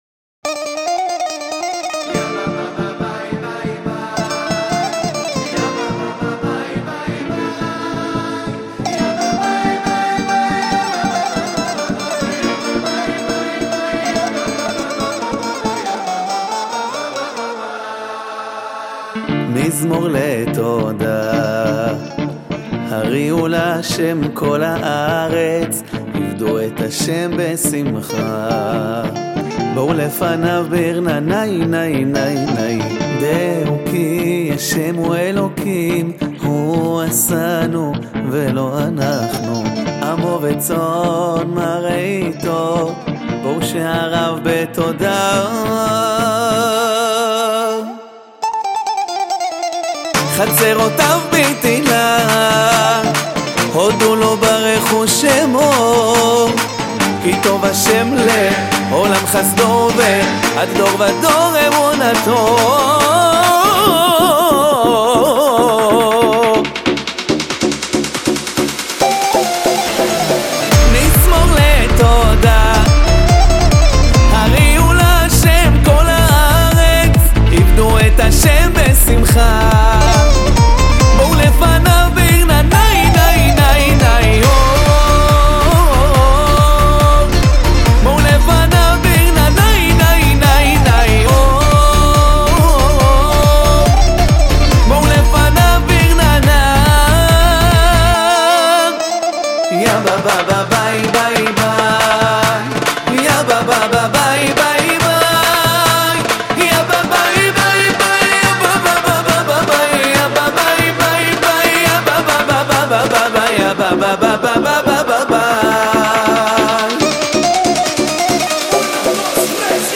החזן והש"ץ
משיק סינגל חדש ומרגש